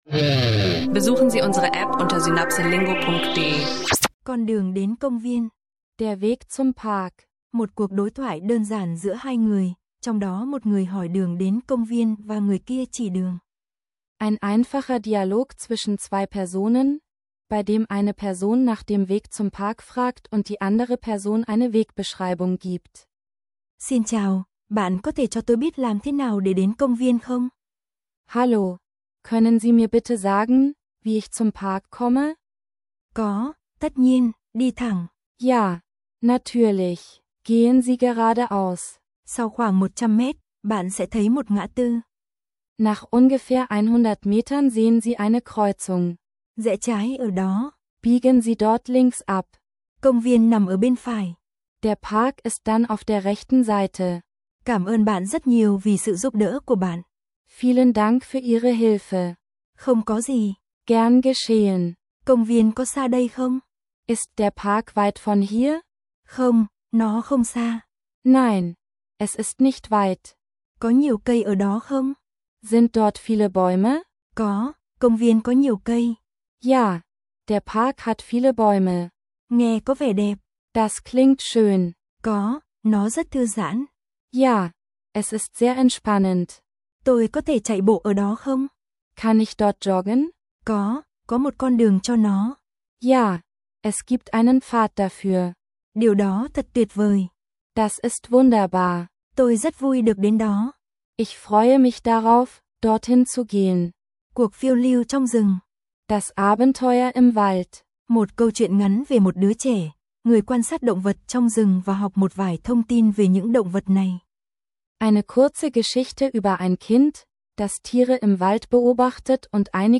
In dieser Episode entdeckst du durch einfache Dialoge, wie man nach dem Weg fragt, und übst dabei nützliche Vokabeln für die Reise.